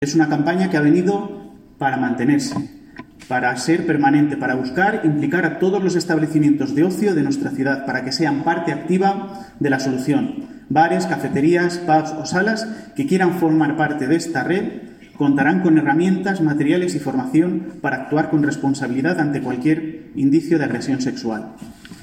Declaraciones del alcalde, Miguel Óscar Aparicio